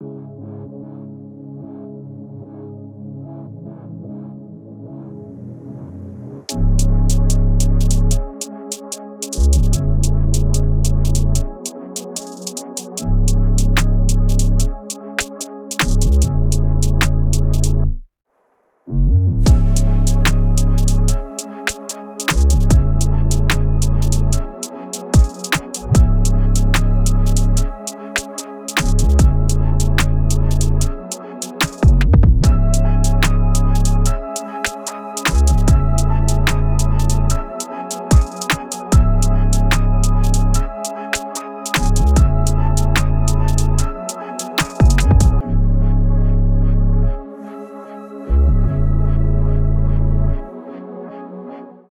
UK Drill sound pack